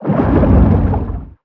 Sfx_creature_shadowleviathan_seatruckattack_loop_water_os_01.ogg